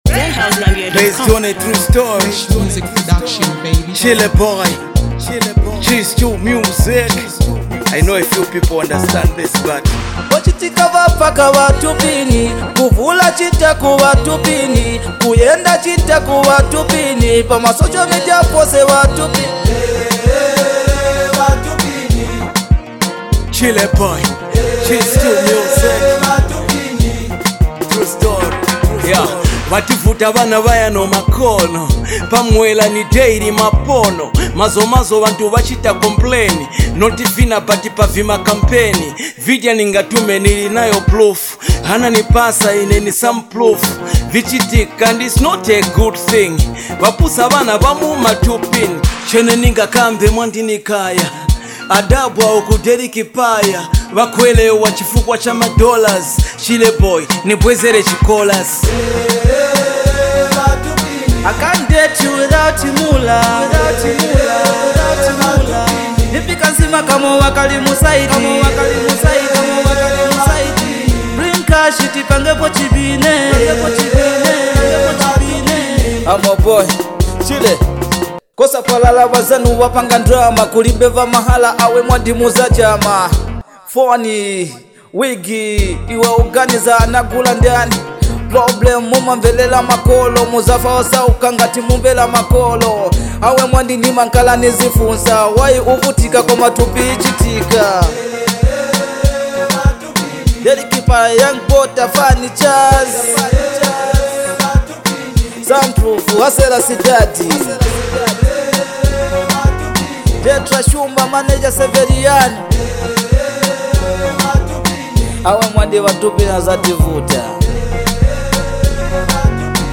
hard-hitting track